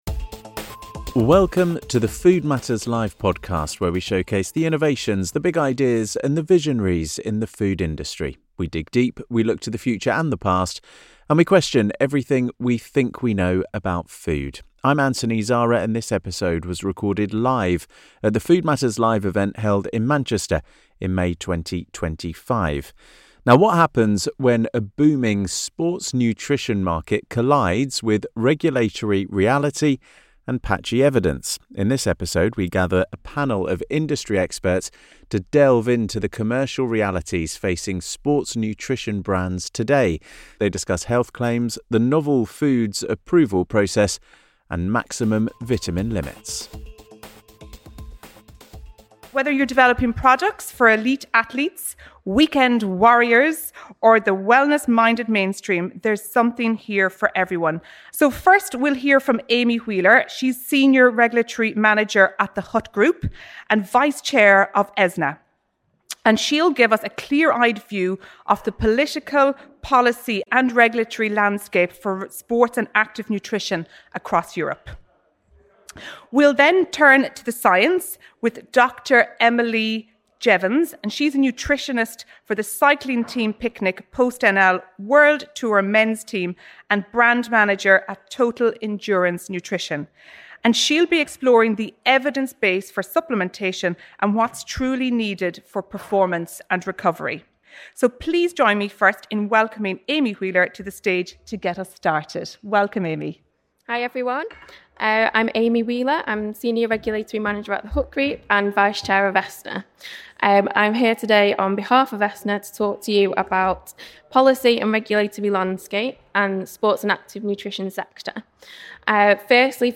In this episode of the Food Matters Live podcast, recorded at our event in Manchester in May 2025, a panel of industry experts delve into the commercial realities facing sports nutrition brands today. They discuss health claims, the novel foods approval process, and maximum vitamin limits.